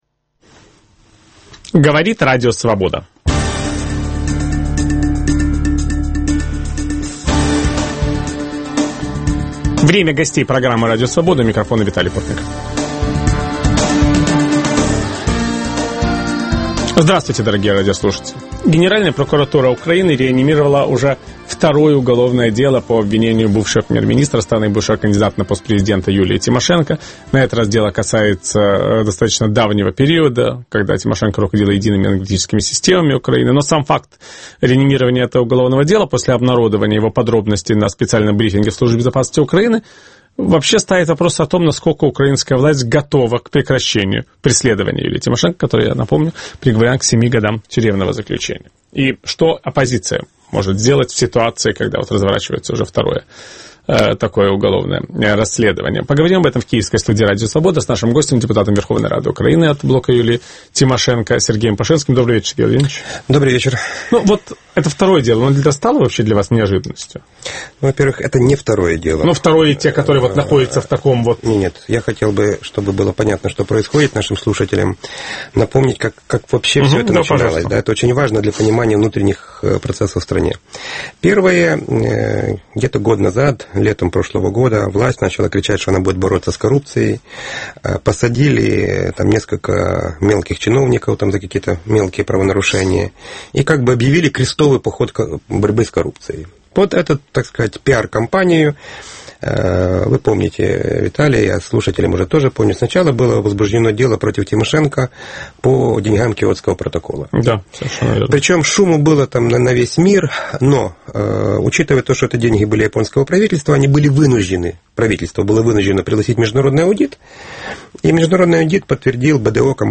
В программе участвует депутат Верховной Рады Украины Сергей Пашинский.